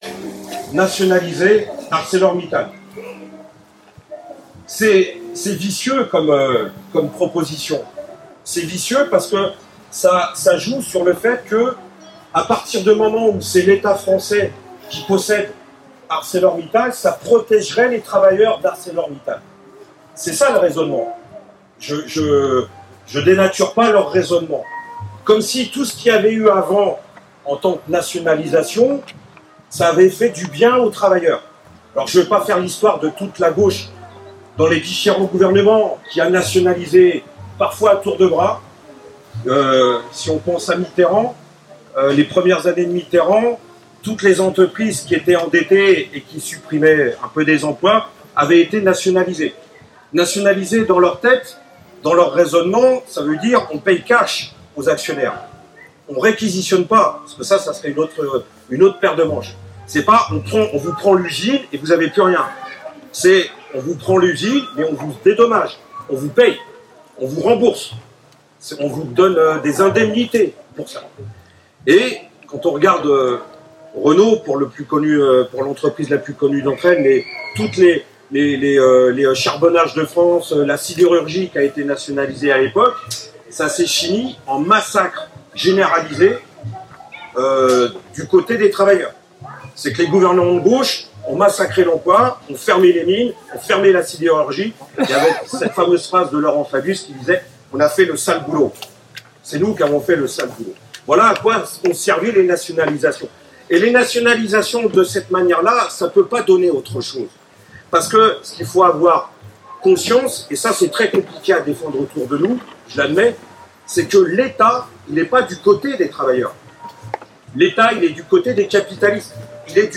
Fête LO 2025 à Marseille